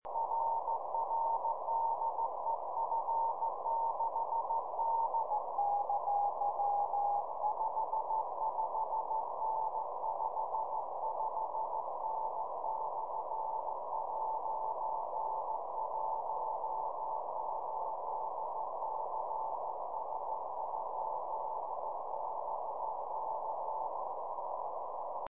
2m: 6el F9FT + 400W PA, 70cm: 22el K1FO + 50W and 40m long cable
CW